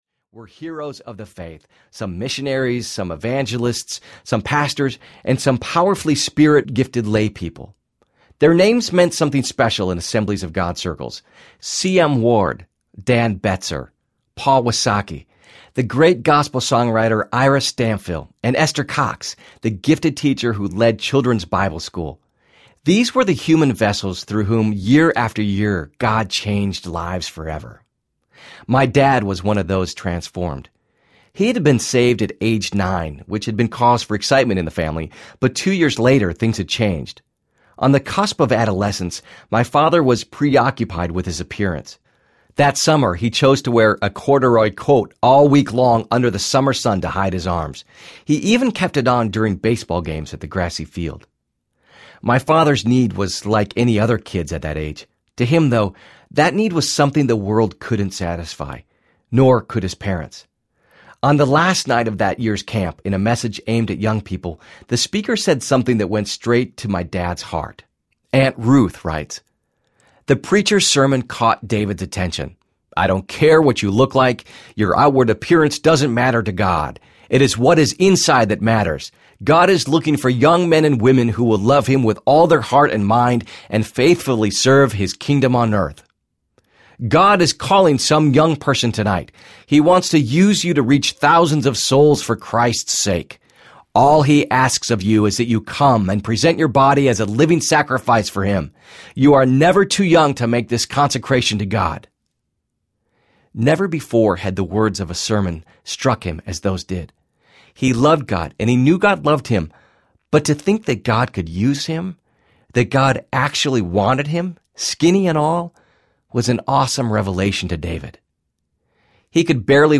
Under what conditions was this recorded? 9.9 Hrs. – Unabridged